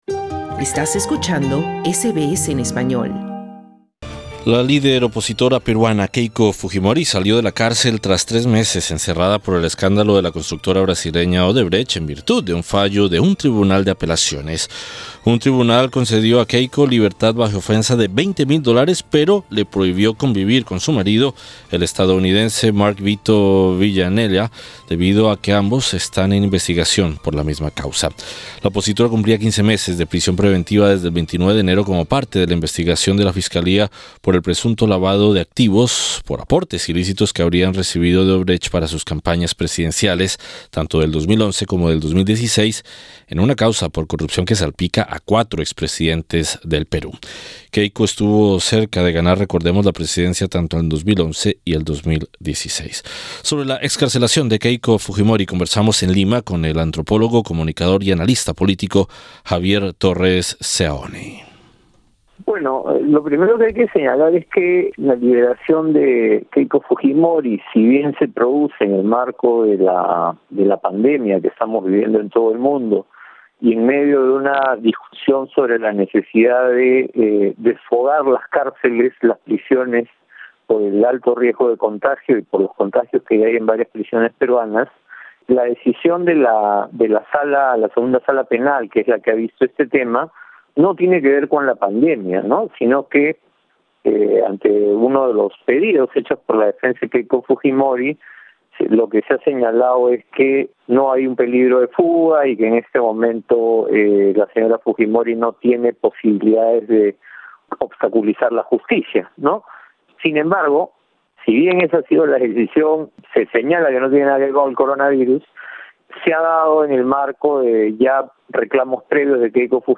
Sobre la excarcelación de Keiko Fujimori, conversamos en Lima con el antropólogo, comunicador y analista político